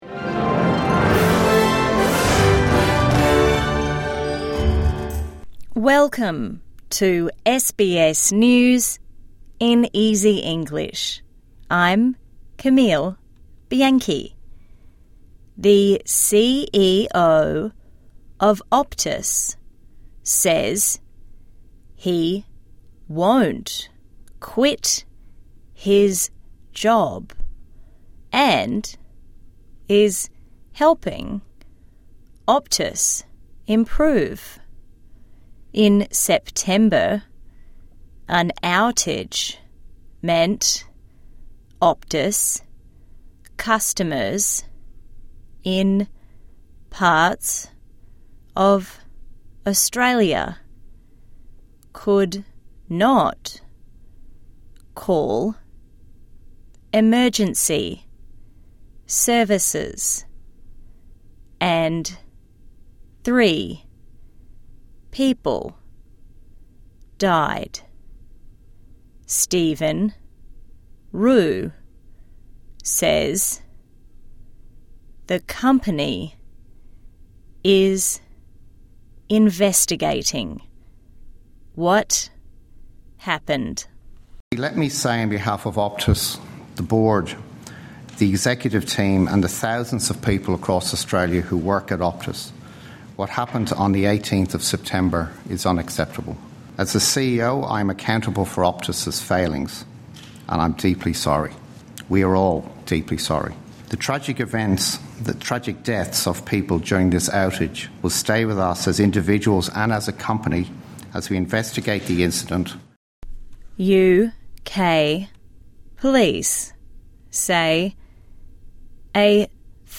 A daily five minute news wrap for English learners and people with disability.